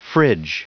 Prononciation du mot fridge en anglais (fichier audio)
Prononciation du mot : fridge